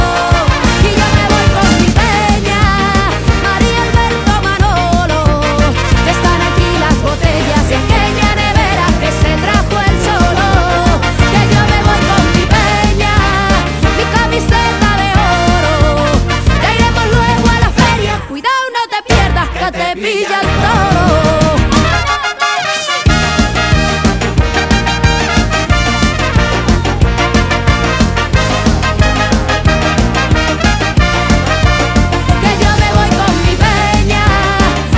Traditional Pop Vocal